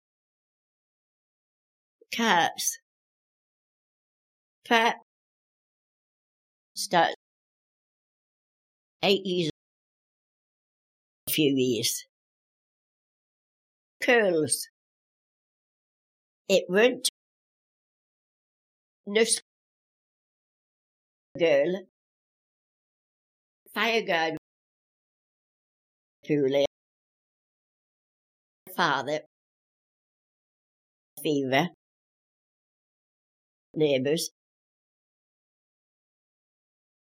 Both are partially rhotic, retaining rhoticity after the NURSE and GOOSE vowels, and NURSE and letTER vowels, respectively (there might be others, the recordings did not include every vowel).
Example 6: Wigan, born in 1904.